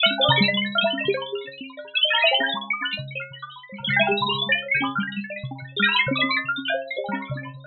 tx_synth_125_trickles.wav